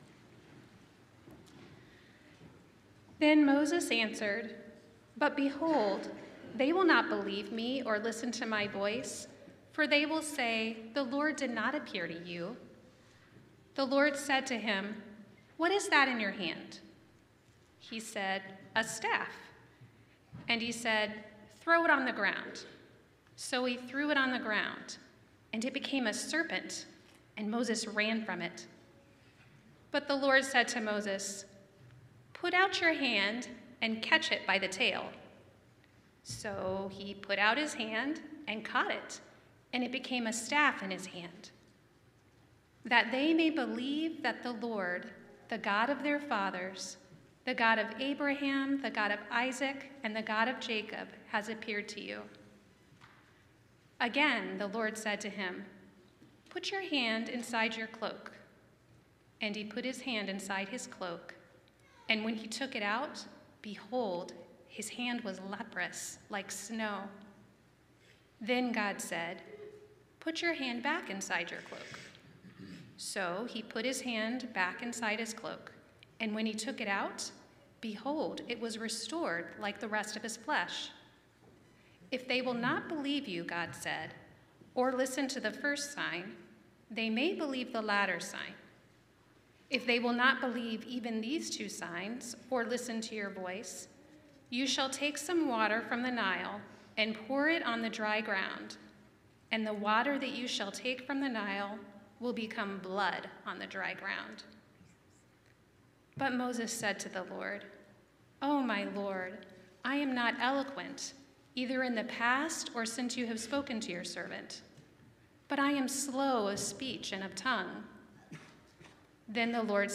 Sermons from Chatham Bible Church in Hazelwood, Missouri.